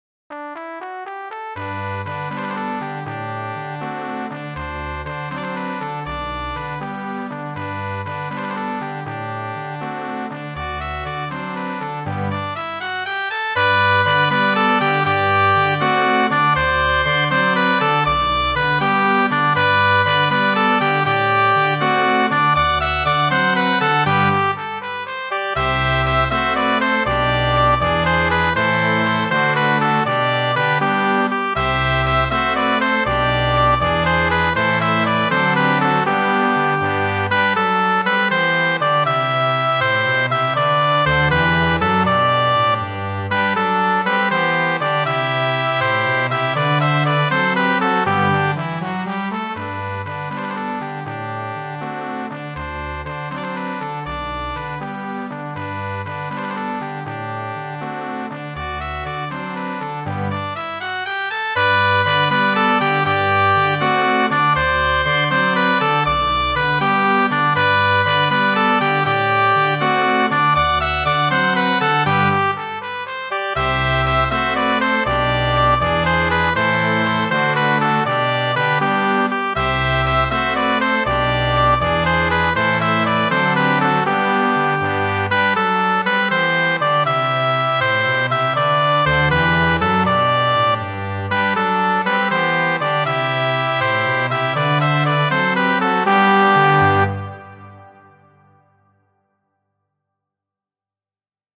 Muziko:
La Himno de Riego, marŝo de la 2-a Hispana Respubliko.